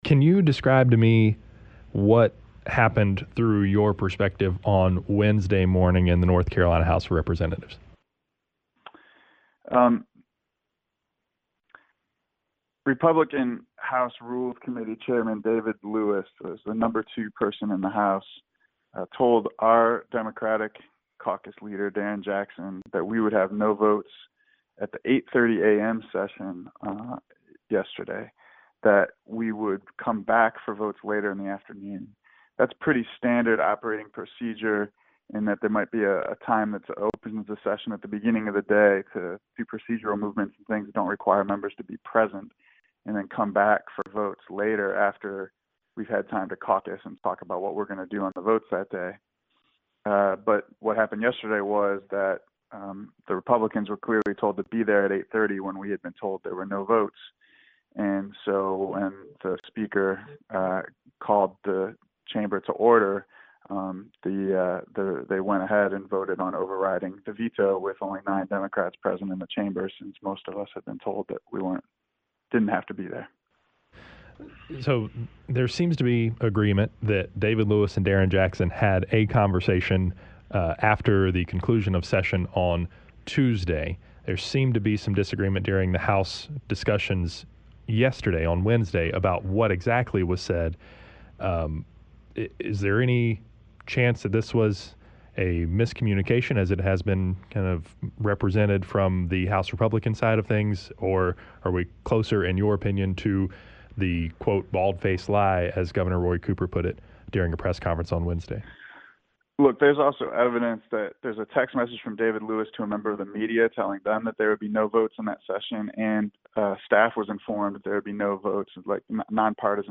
speaks with Democratic State House Rep. Graig Meyer.